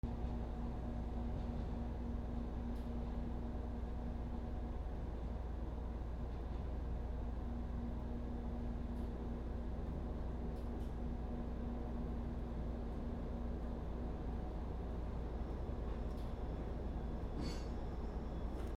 駅環境音
/ E｜乗り物 / E-60 ｜電車・駅 / 2 駅より